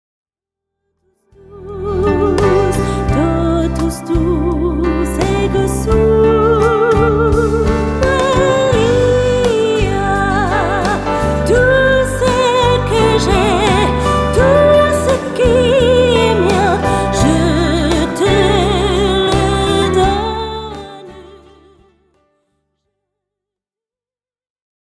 Narrateurs